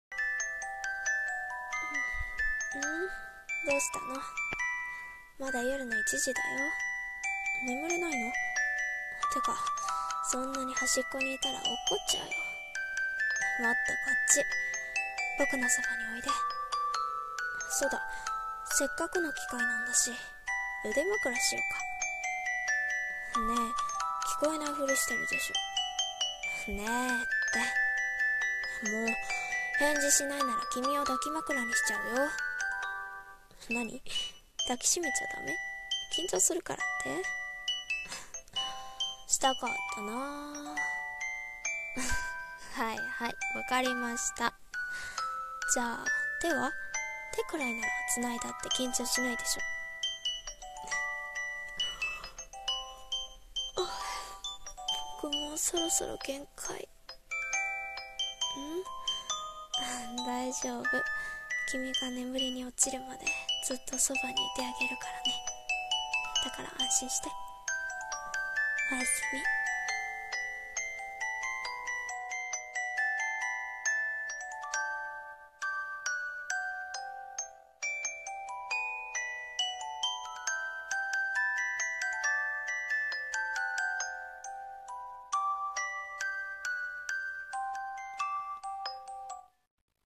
1人声劇 【添い寝】